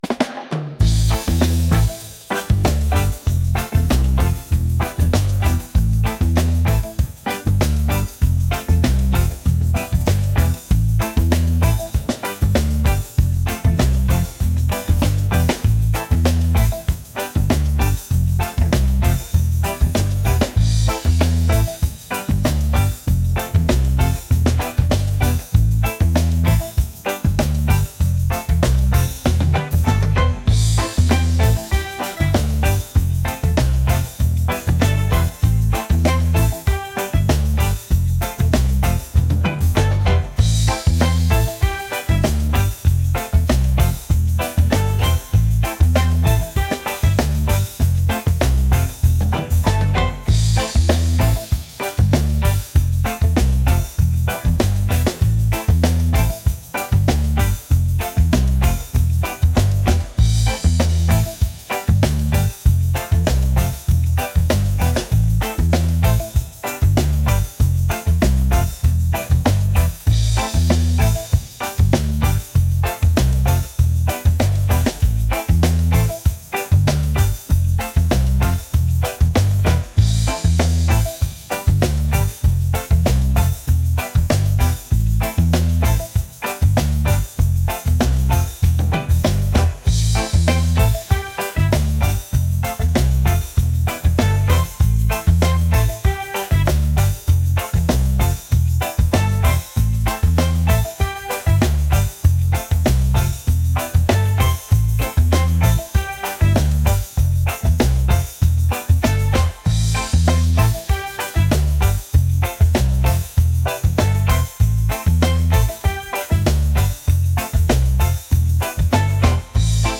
soulful | reggae | upbeat